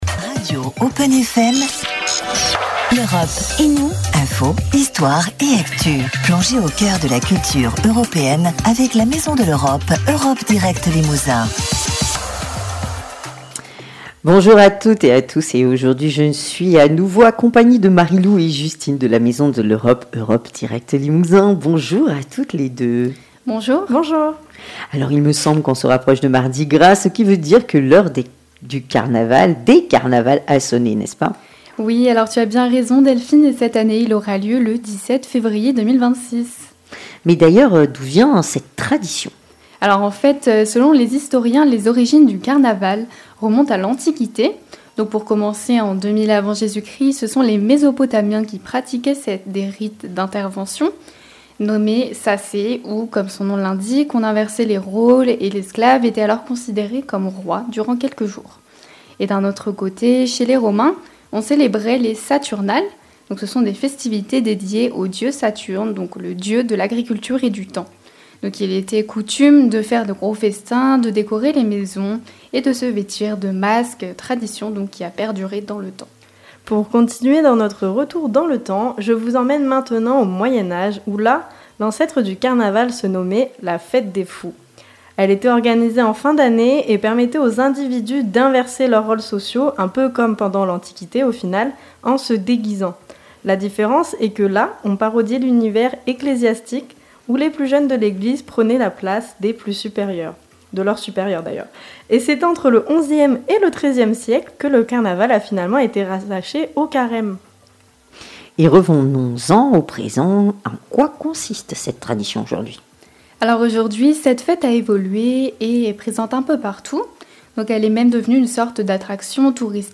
CARNAVAL-Chronique-5-fevrier.mp3